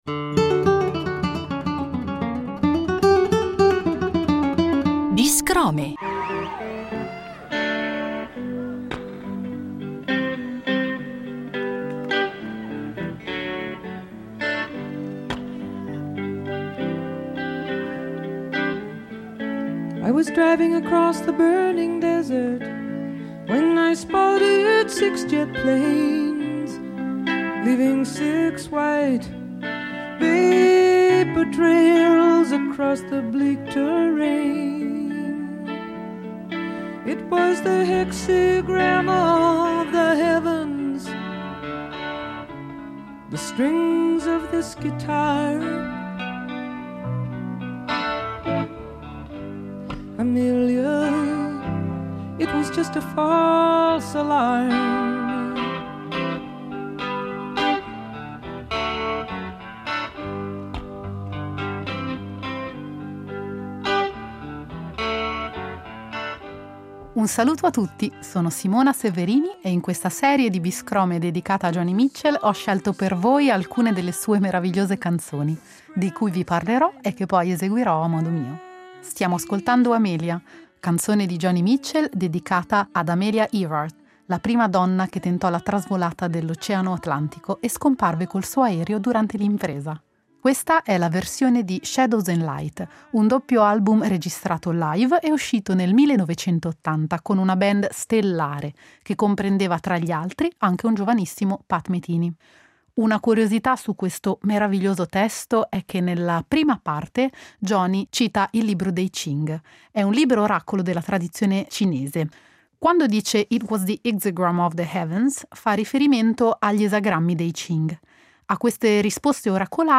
ogni puntata è impreziosita dalle sue interpretazioni da sola, con la chitarra